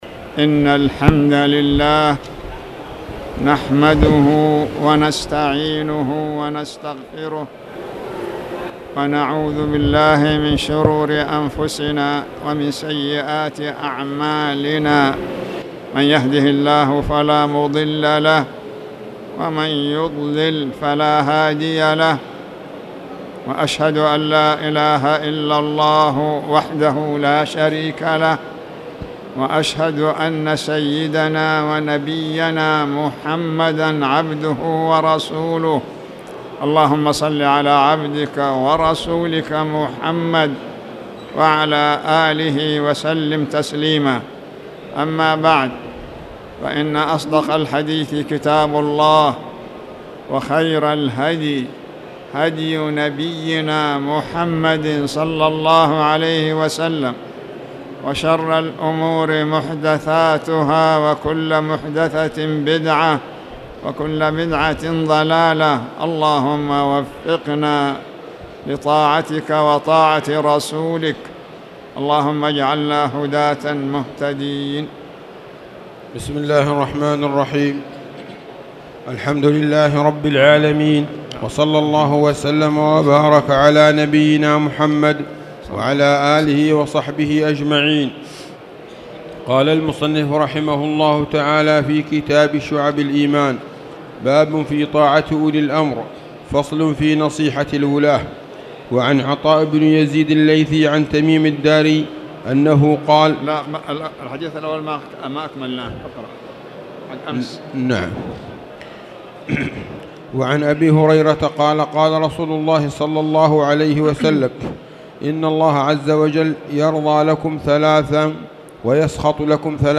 تاريخ النشر ٢٥ ذو الحجة ١٤٣٧ هـ المكان: المسجد الحرام الشيخ